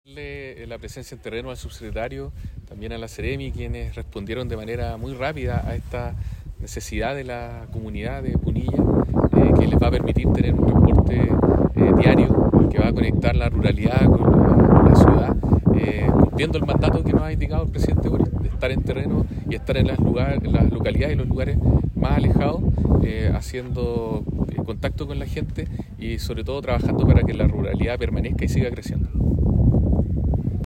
El delegado presidencial provincial de Limarí, Galo Luna, agradeció el trabajo de las autoridades de transporte con este servicio